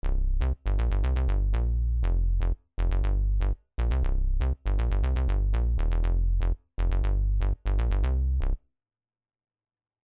名前の通り、低域の音圧感のあるシンセベースでアタック感もあって良いのですが、音色の変化が少々速めに設定されており、全体的なトーンが暗めのサウンドになっています。